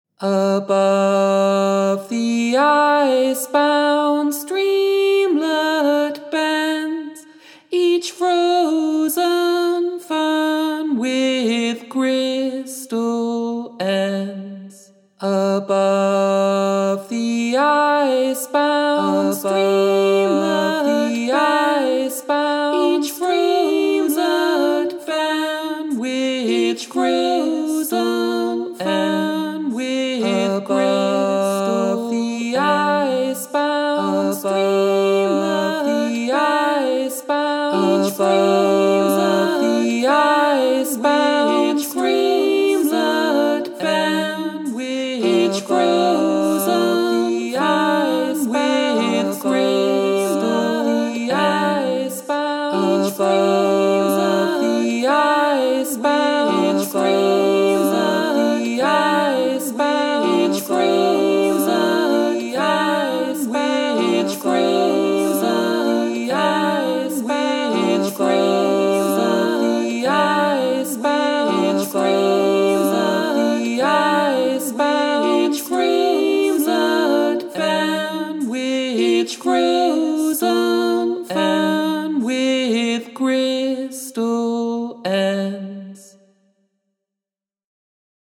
Rounds and Canons